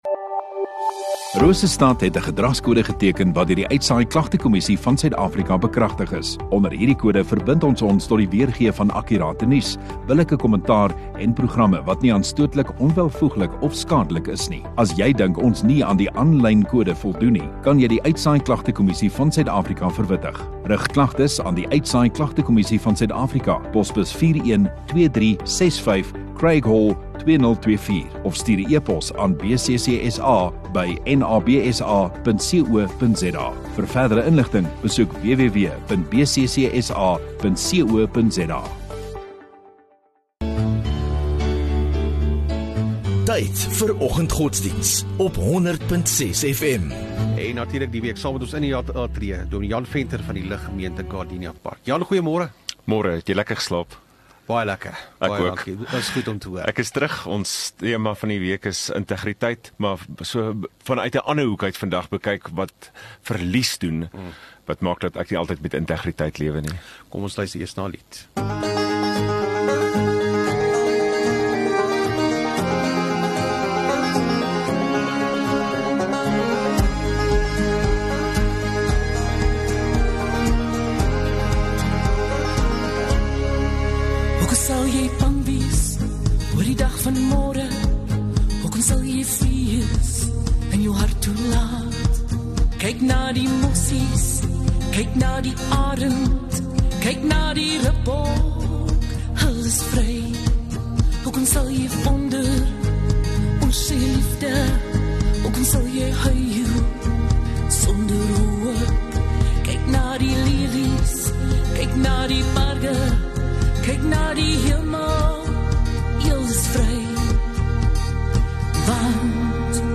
7 Nov Donderdag Oggenddiens